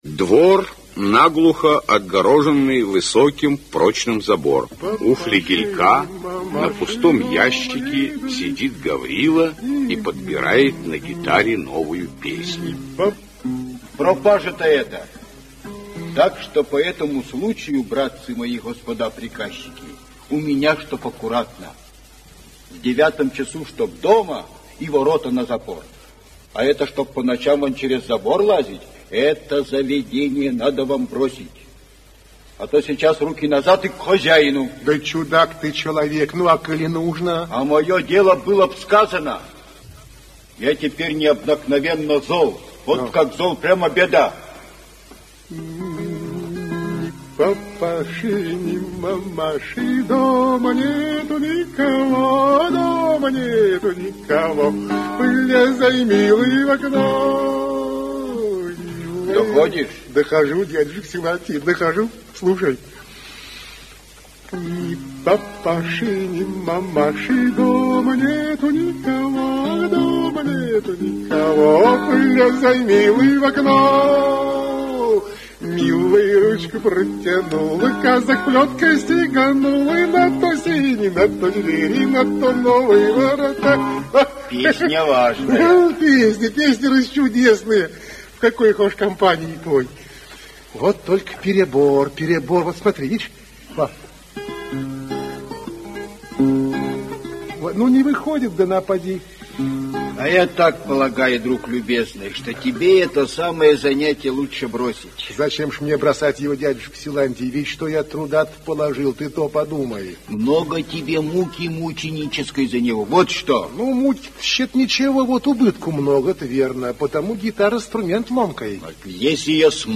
Аудиокнига Горячее сердце (спектакль) | Библиотека аудиокниг
Aудиокнига Горячее сердце (спектакль) Автор Александр Островский Читает аудиокнигу К. Н. Еланская.